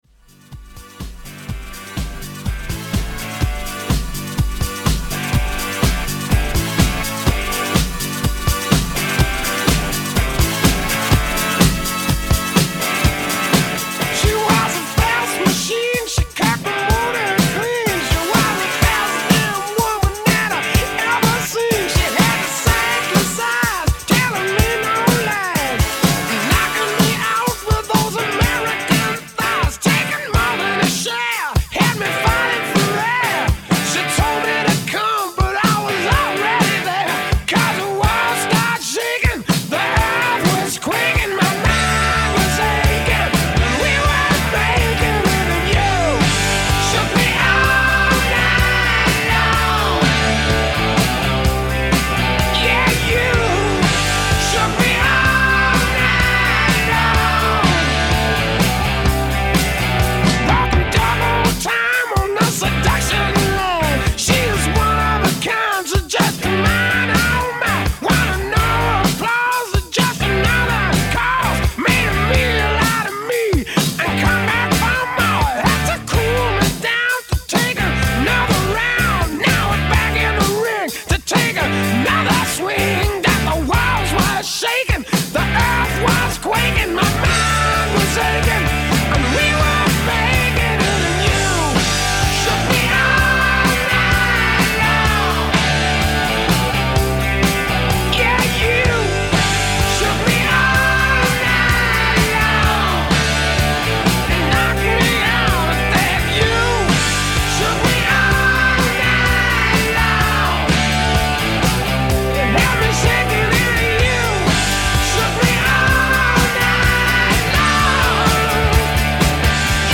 A live classic rock, hip-hop, and pop mix